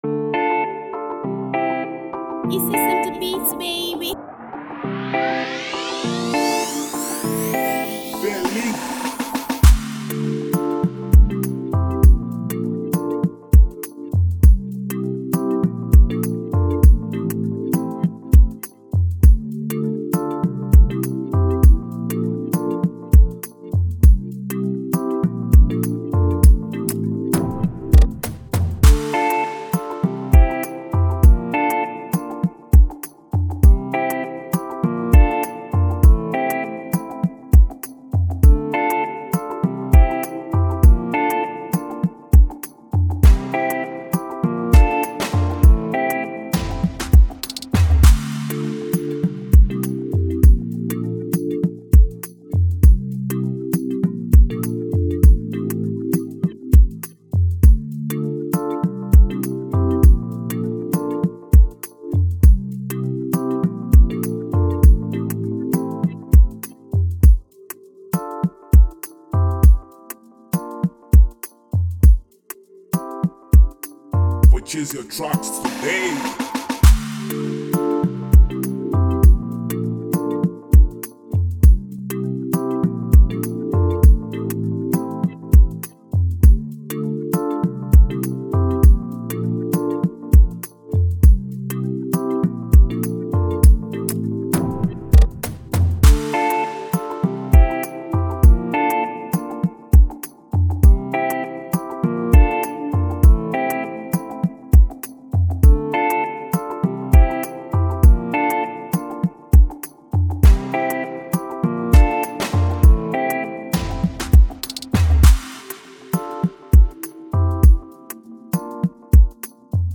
free beat instrumental